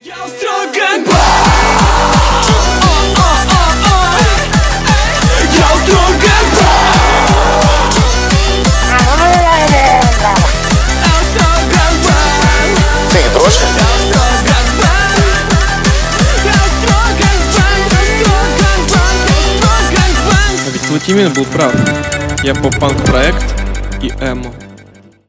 Рок Металл
Поп Музыка